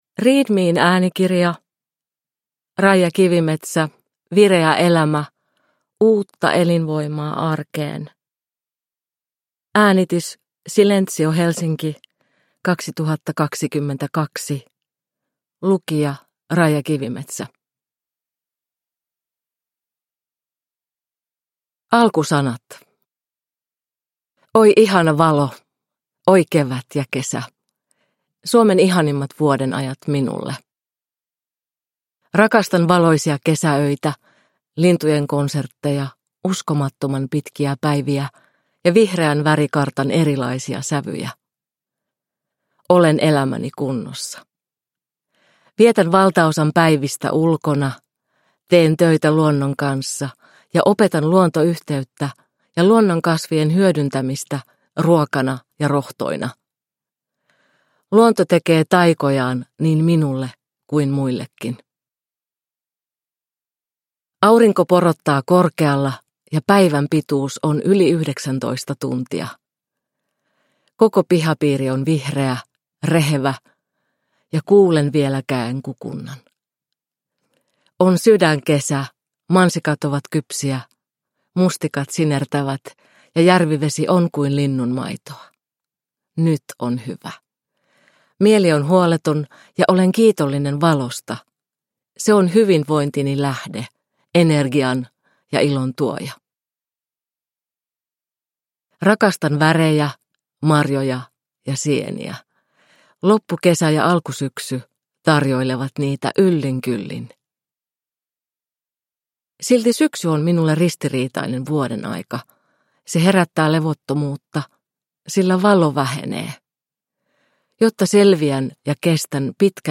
Vireä elämä – Ljudbok – Digibok